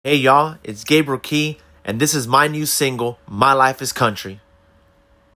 a high-energy anthem
Radio Stinger